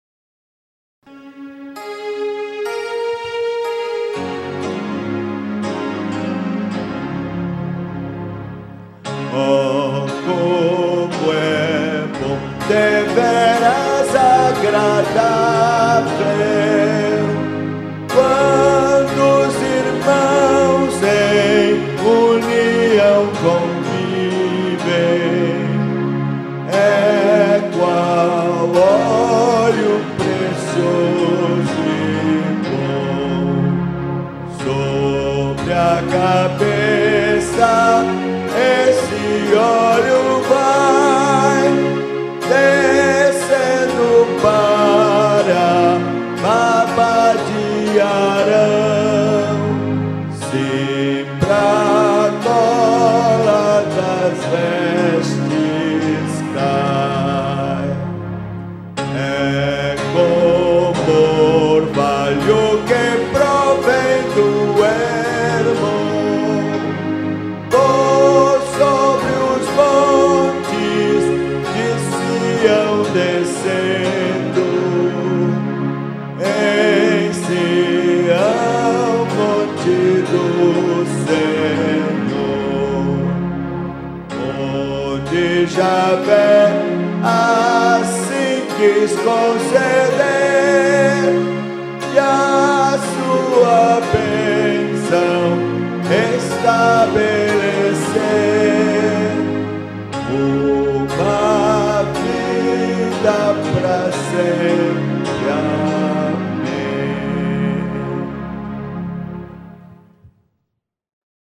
Modo: jônio
Harmonização: Claude Goudimel, 1564
salmo_133A_cantado.mp3